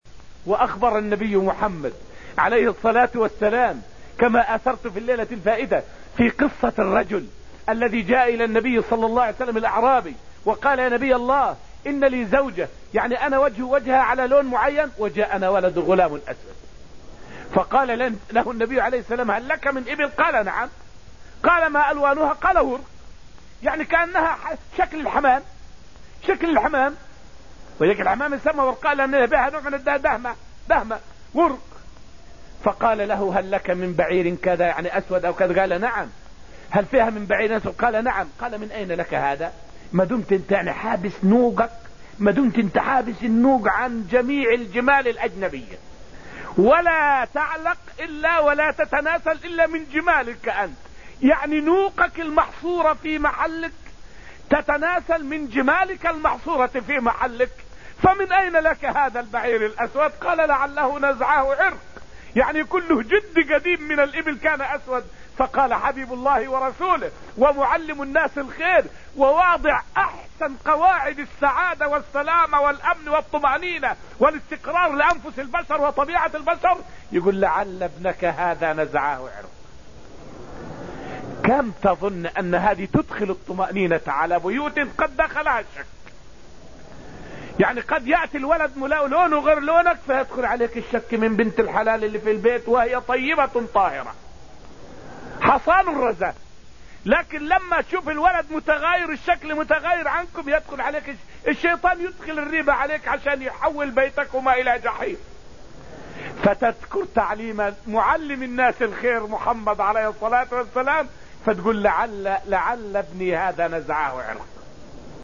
فائدة من الدرس الخامس من دروس تفسير سورة الواقعة والتي ألقيت في المسجد النبوي الشريف حول كيف تعامل النبي مع الأعرابي الذي ولد له غلام أسود؟